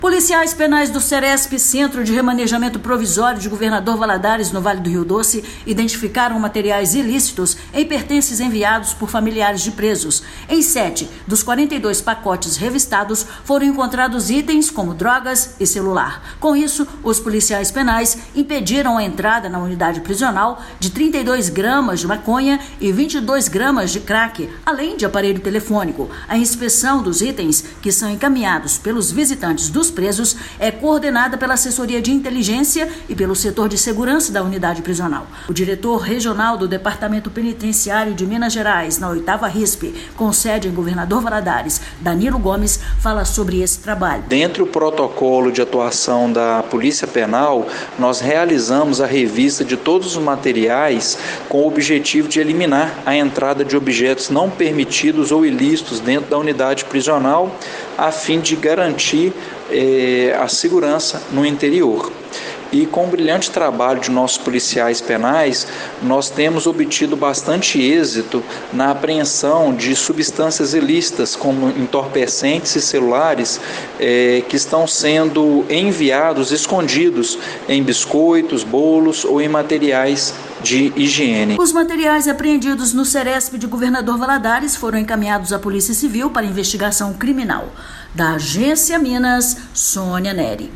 [RÁDIO] Policiais penais impedem entrada de materiais ilícitos em Ceresp de Governador Valadares
Policiais penais do Centro de Remanejamento Provisório (Ceresp) de Governador Valadares I, na região do Rio Doce, identificaram materiais ilícitos em pertences enviados por familiares de presos, durante procedimento padrão de revista nos itens de complementação enviados nessa terça-feira (5/1). Ouça a matéria de rádio.